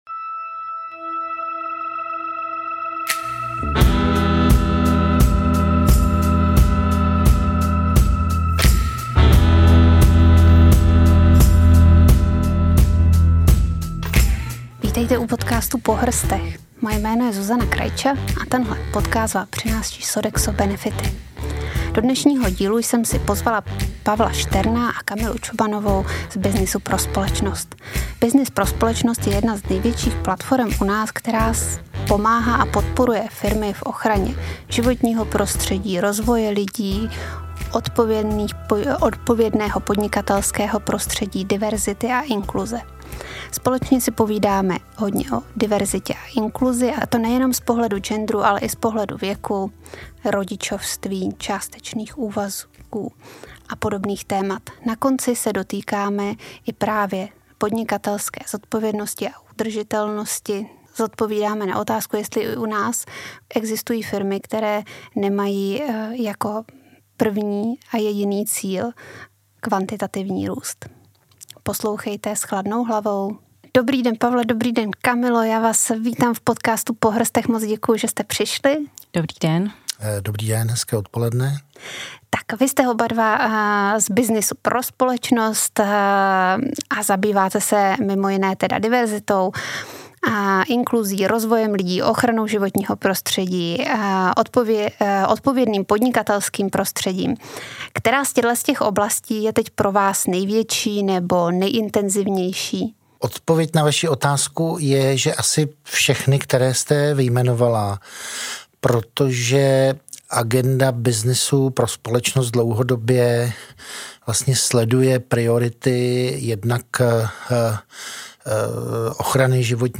V rozhovoru si povídáme o diverzitě nejen z pohledu genderu, ale i věku, rodičovství, částečných úvazků, dále se bavíme o podnikatelské zodpovědnosti a udržitelnosti a na závěr odpovídáme na otázku, jestli u nás existují firmy, které nemají jako primární cíl kvantitativní růst.